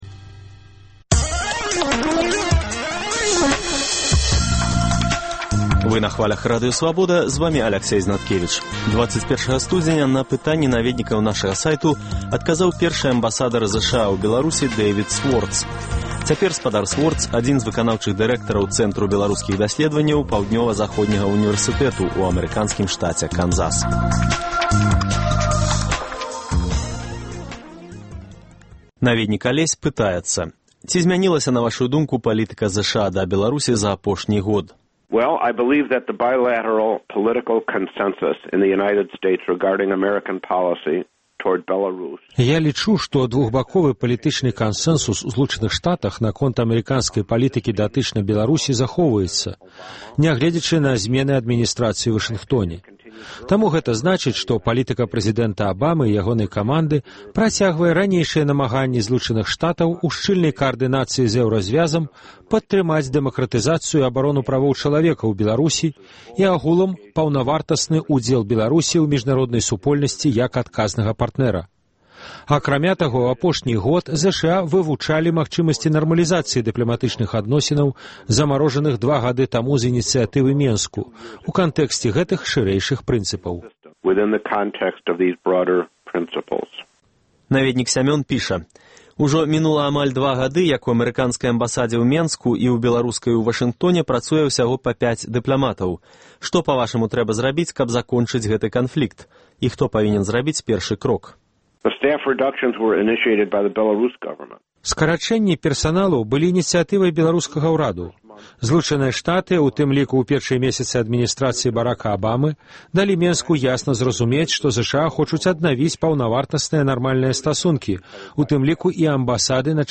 Запіс адказаў першага амбасадара ЗША ў Беларусі Дэйвіда Суорца на пытаньні наведнікаў нашага сайту.